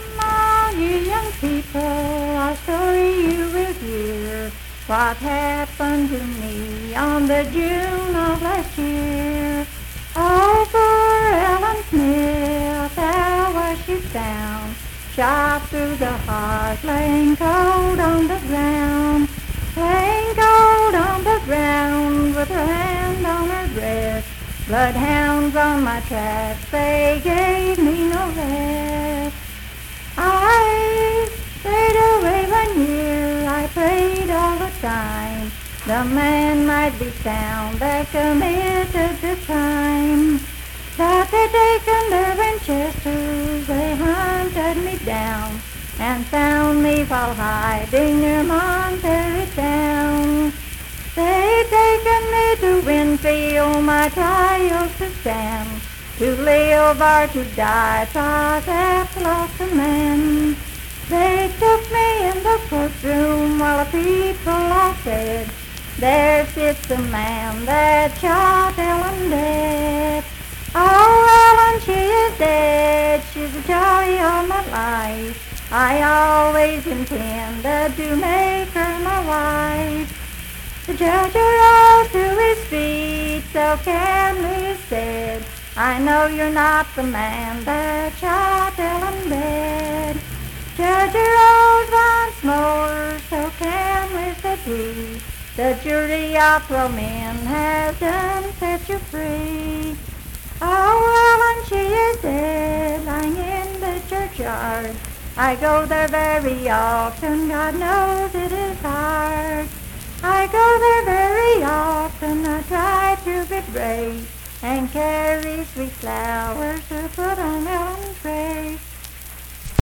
Unaccompanied vocal music
Verse-refrain 12(2).
Voice (sung)
Spencer (W. Va.), Roane County (W. Va.)